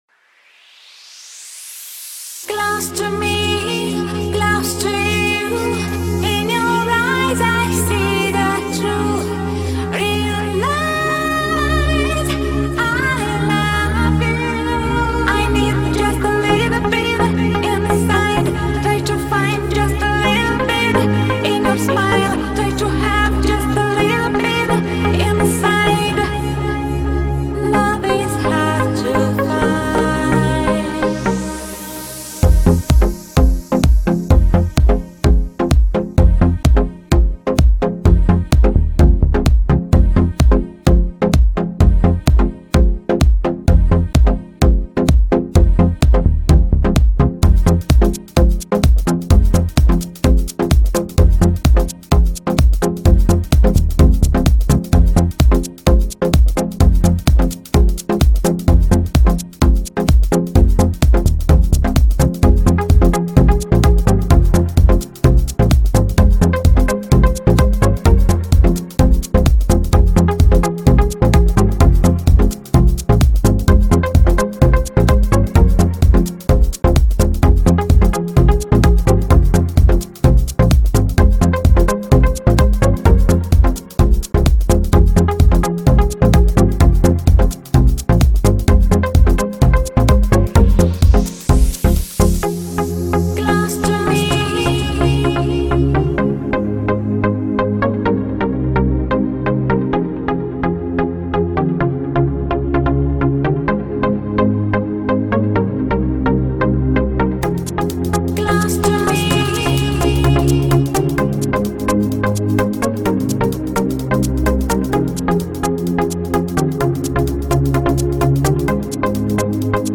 Главная » Музыка » Dance mix